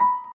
piano_last15.ogg